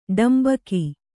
♪ ḍambaki